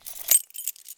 household
Key Chain Zip 3